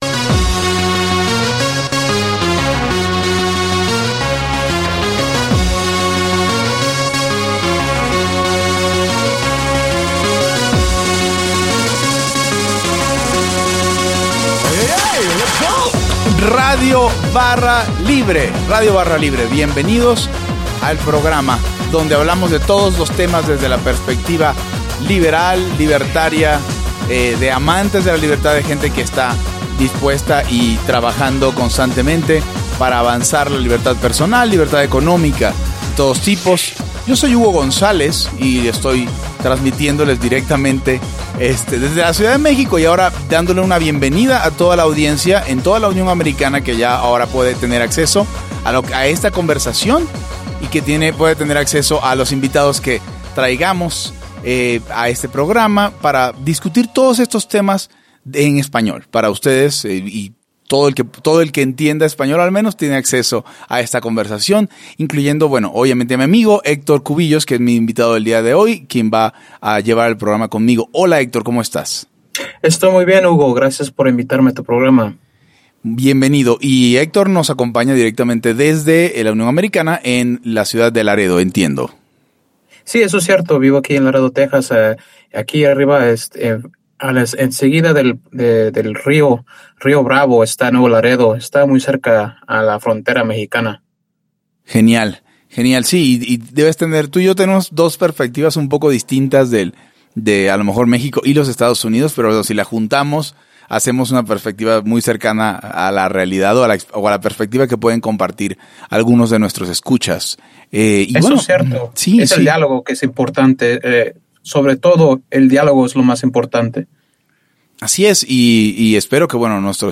Converso